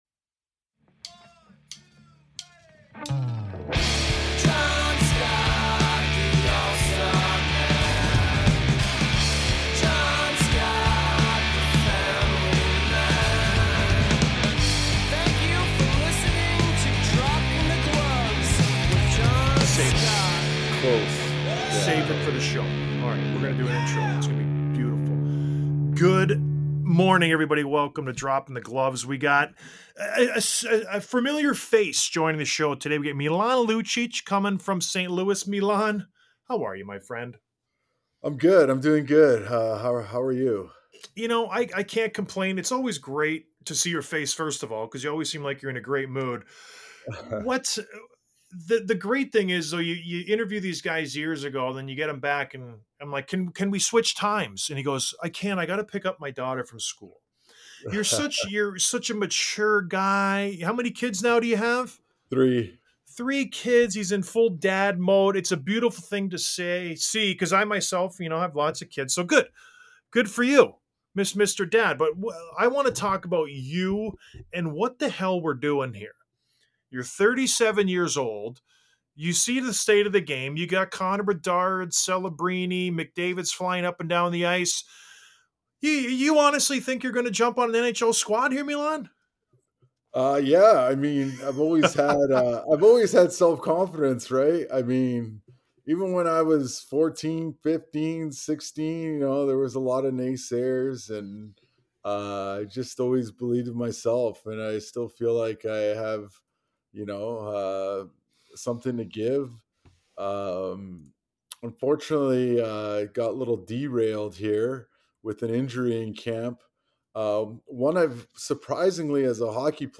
Milan Lucic of the St. Louis Blues Interview